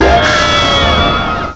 cry_not_giratina.aif